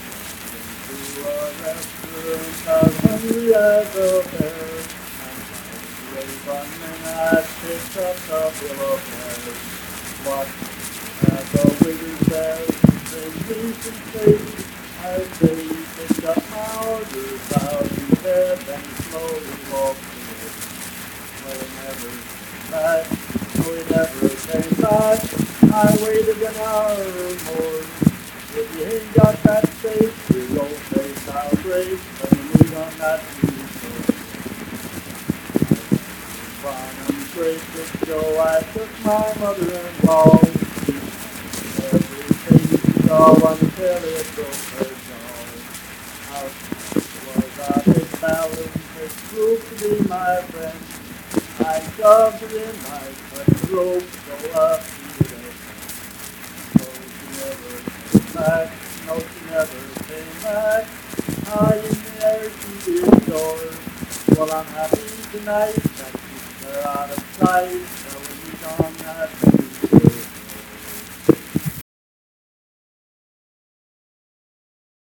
Unaccompanied vocal music performance
Verse-refrain 2(12w/R).
Voice (sung)